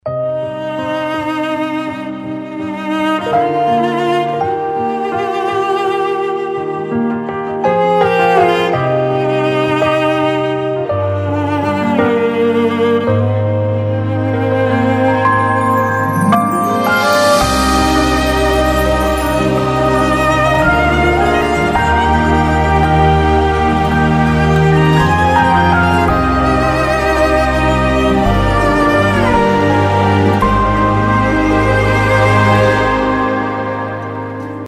رینگتون احساسی و بی کلام